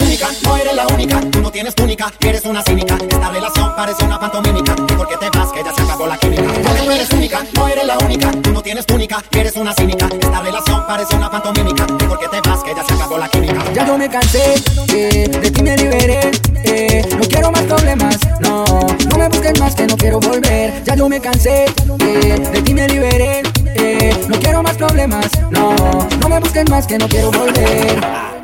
Genere: latin, reggaeton